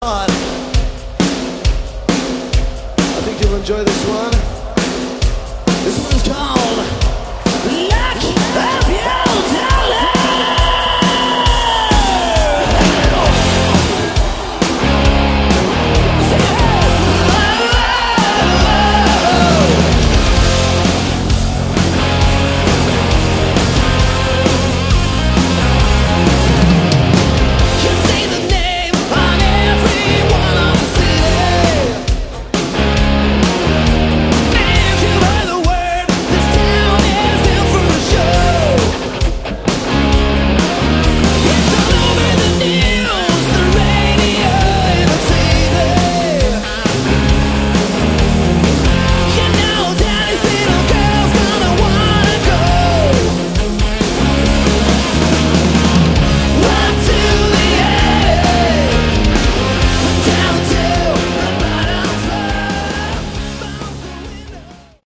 Category: Sleaze Glam
vocals
guitar
drums
bass